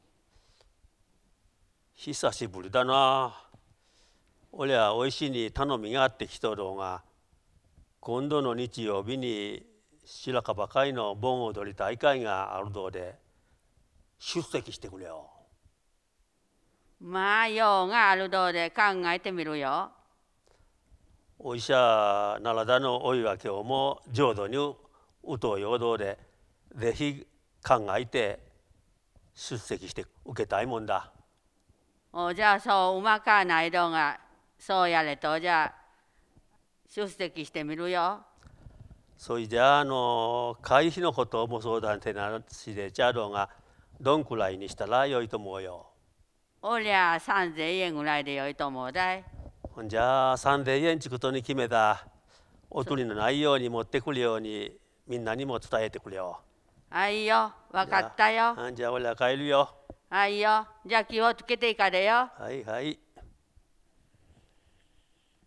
早川町のことば：雨畑・茂倉・奈良田の音声資料集|いずこに
会話（ロールプレイ） ─奈良田─